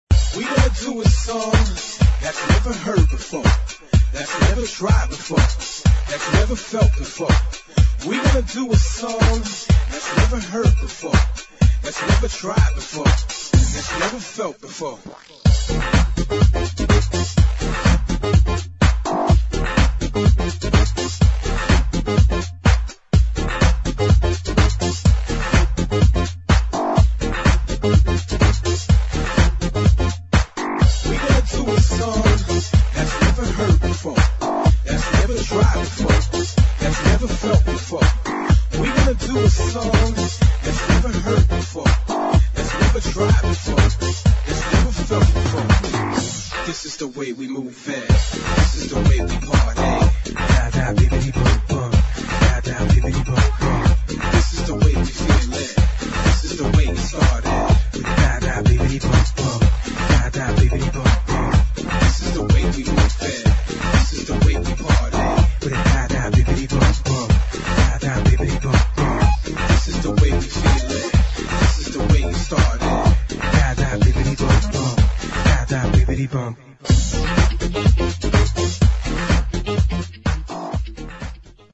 [ HOUSE | HIP HOUSE | FUNKY HOUSE ]